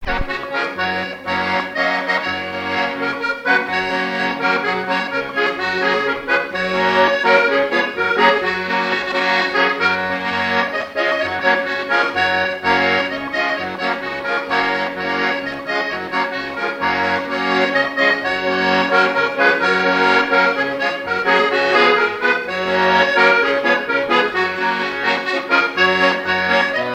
danse : ronde : rond de l'Île d'Yeu
Genre strophique
Témoignages sur la pêche, accordéon, et chansons traditionnelles
Pièce musicale inédite